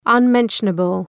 Shkrimi fonetik {ʌn’menʃənəbəl}